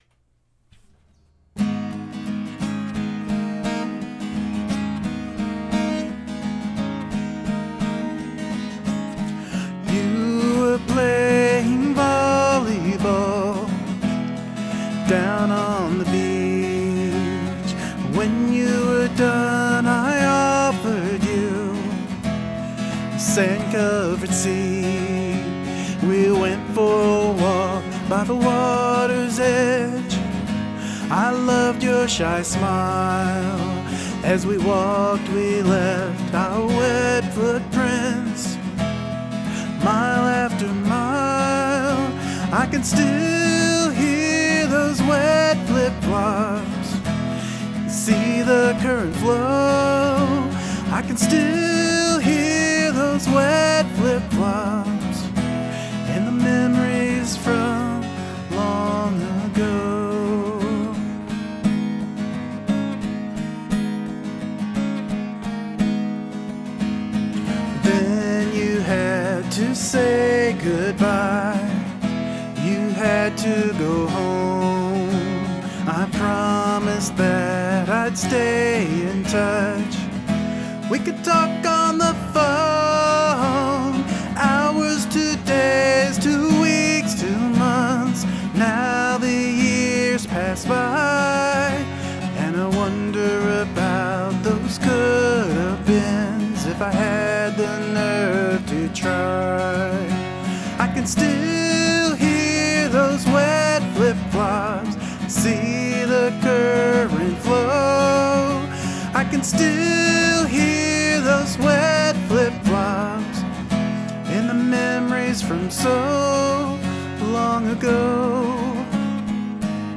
Guitar and Vox